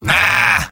Robot-filtered lines from MvM. This is an audio clip from the game Team Fortress 2 .
{{AudioTF2}} Category:Engineer Robot audio responses You cannot overwrite this file.